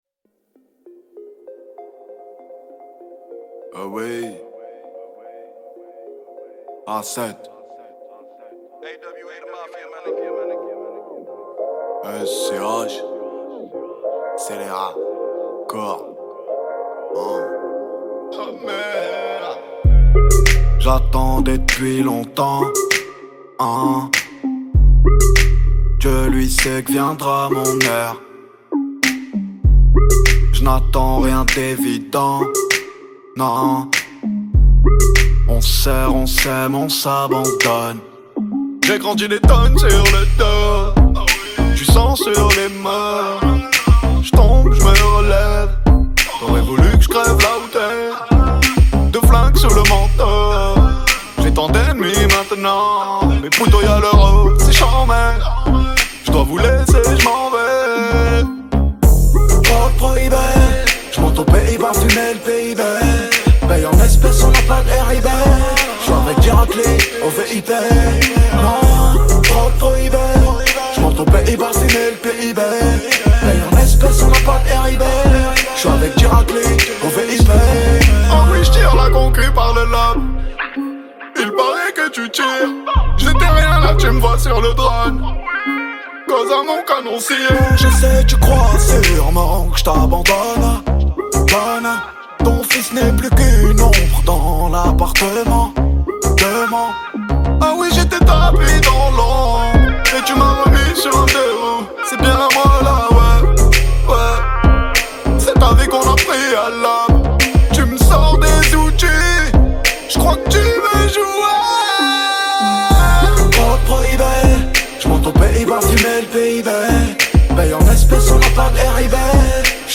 43/100 Genres : french rap, pop urbaine Télécharger